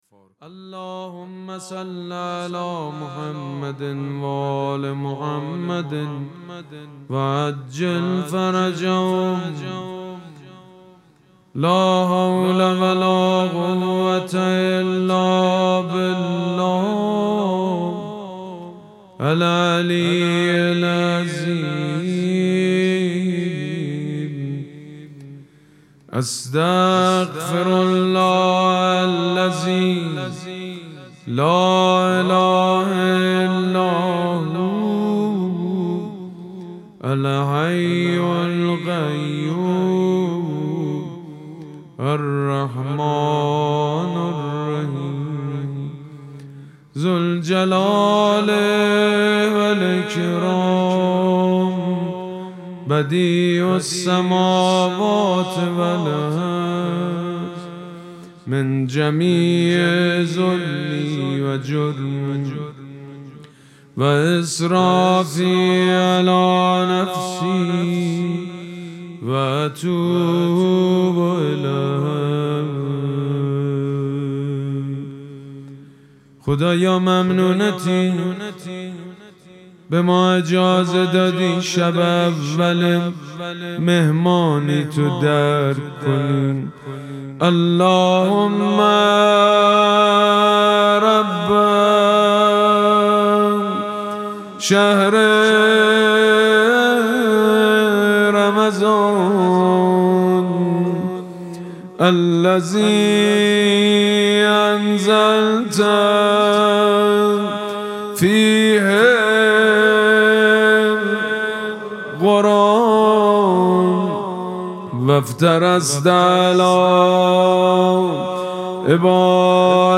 مراسم مناجات شب اول ماه مبارک رمضان شنبه ۱۱ اسفند ماه ۱۴۰۳ | ۳۰ شعبان ۱۴۴۶ حسینیه ریحانه الحسین سلام الله علیها
سبک اثــر مناجات مداح حاج سید مجید بنی فاطمه